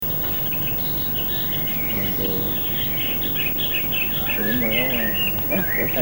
/ 142 kb): Sangeren høres i baggrunden. Desuden høres lidt snak og en Solsort.